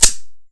Index of /server/sound/weapons/fokku_tc_usp
fiveseven_slidepull.wav